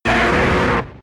Cri de Reptincel K.O. dans Pokémon X et Y.